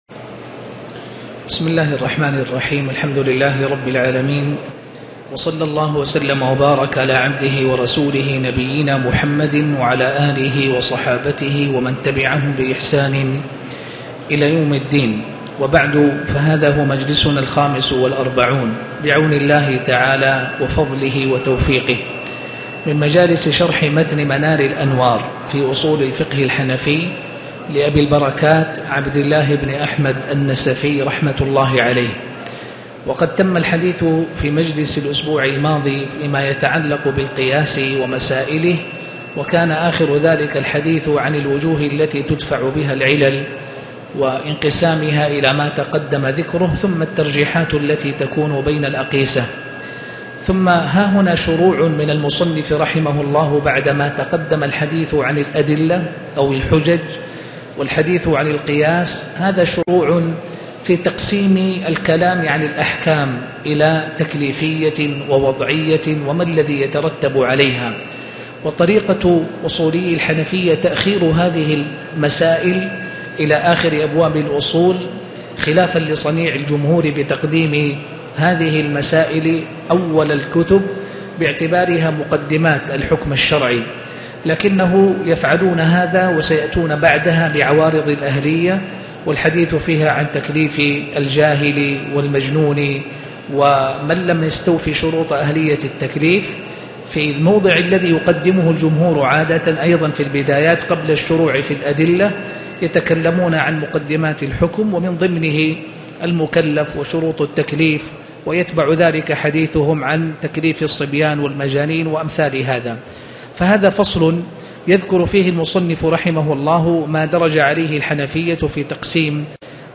شرح منار الانوار الدرس 45